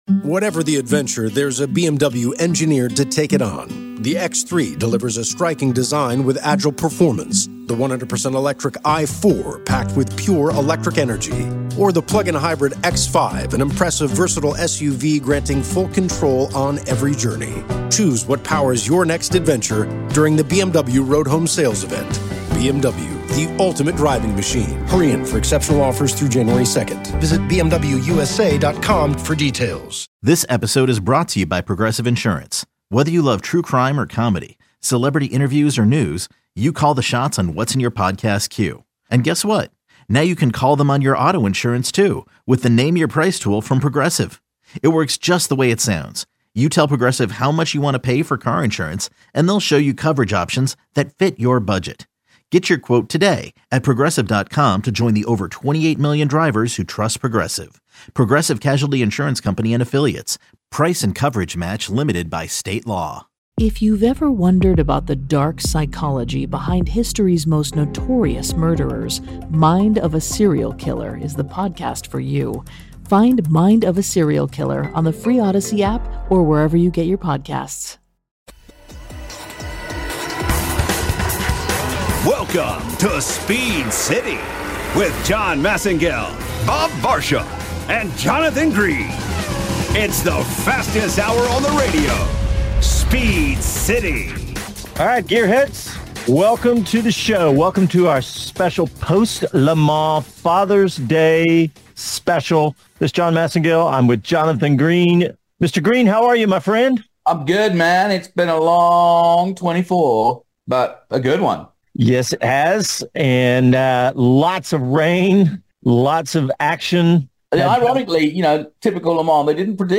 Our post-Le Mans recap on a Fathers Day... plus a special interview with McLaren Racing CEO Zak Brown and McLaren Automotive CEO Michael Leiters.